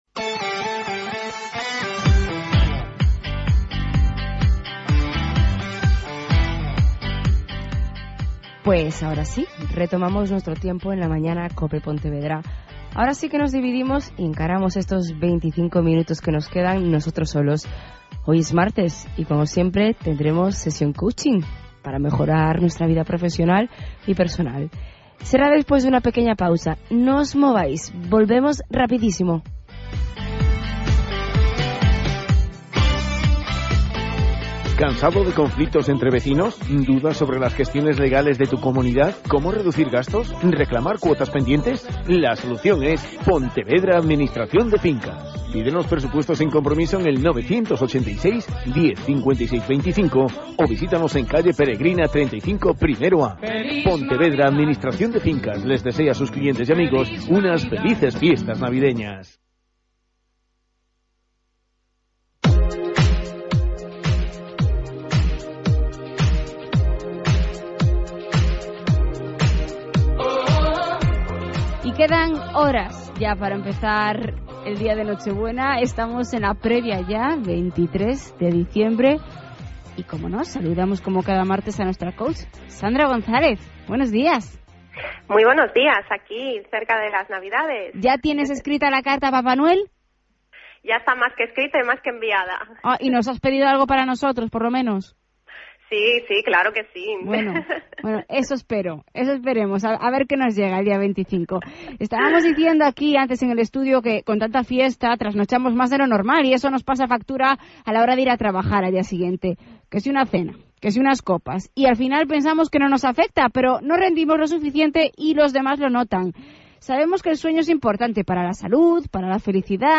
Radio Podcast Programa «La Mañana» en COPE Pontevedra, hablando de las fiestas. 23 Diciembre 2014
Mi intervención comienza en el minuto 8:29 hasta el minuto 17:00